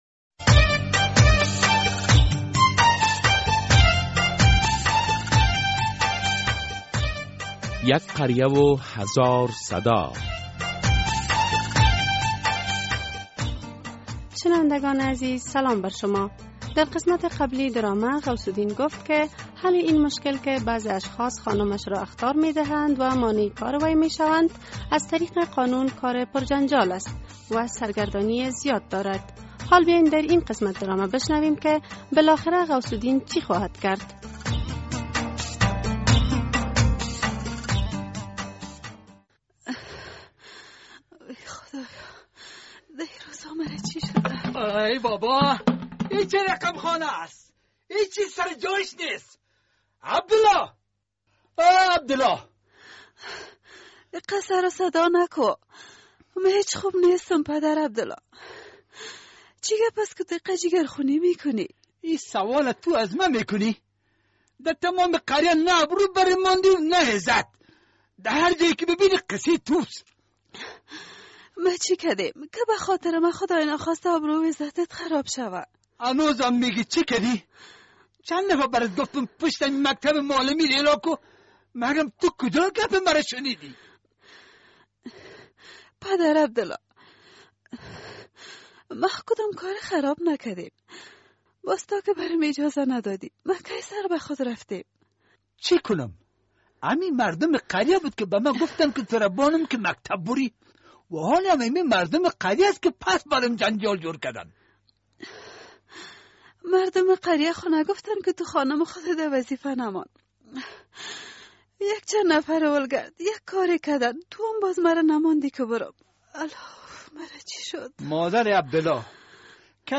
در این درامه که موضوعات مختلف مدنی، دینی، اخلاقی، اجتماعی و حقوقی بیان می‌گردد هر هفته به روز های دوشنبه ساعت ۳:۳۰ عصر از رادیو آزادی ...